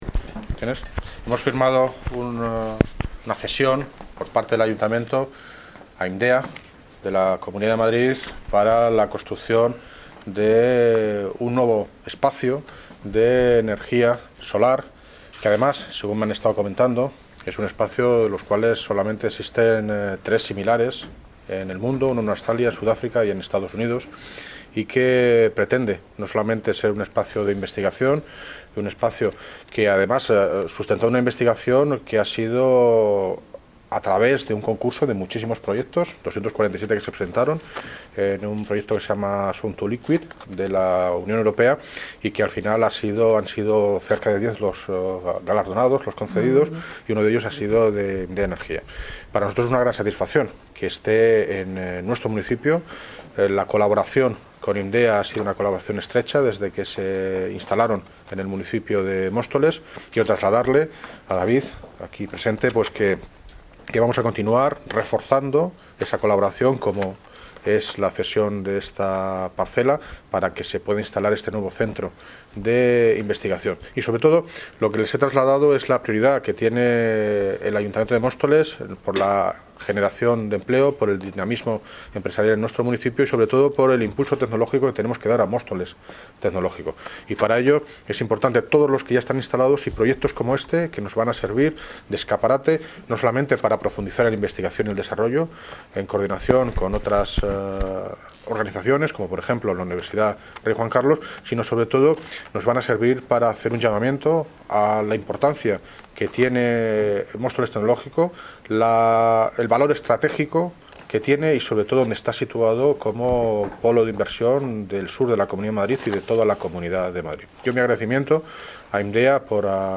Audio - David Lucas (Alcalde de Móstoles) Sobre convenio IMDEA ENERGIA